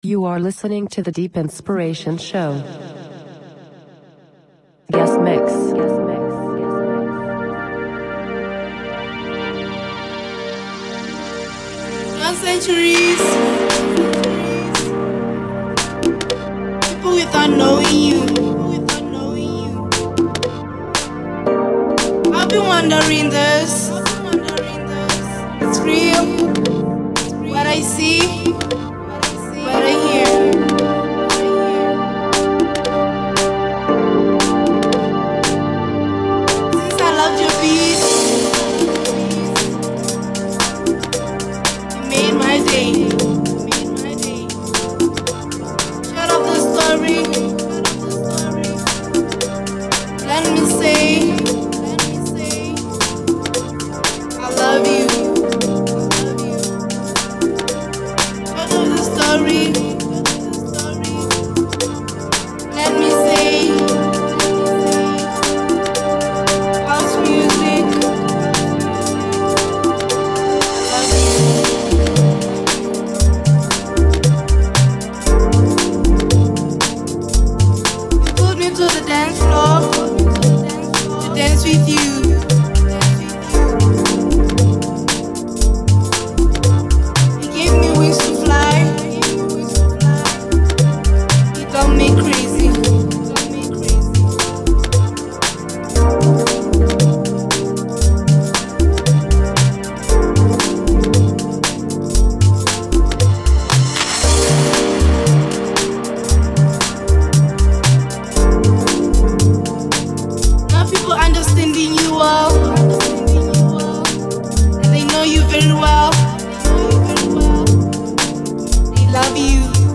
This time with a nice deep selection by dj and producer